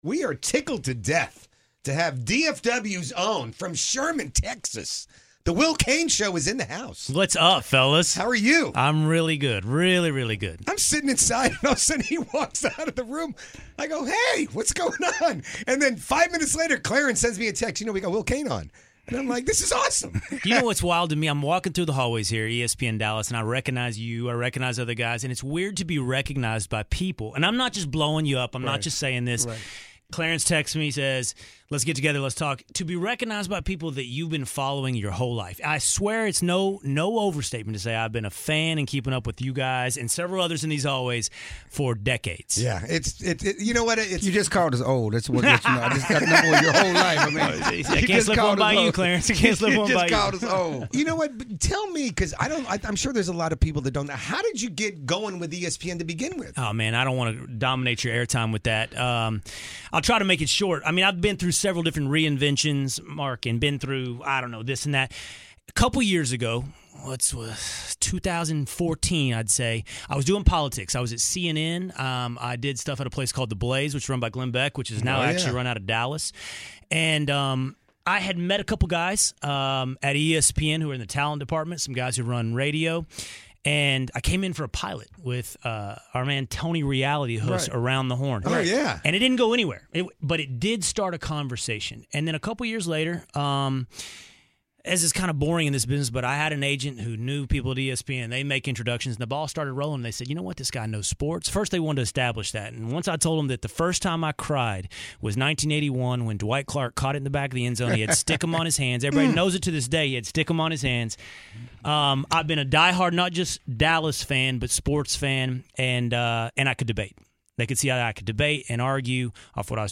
in studio to look ahead to Cowboys/Seahawks, and talk about Texas's big win over Georgia in the Sugar Bowl.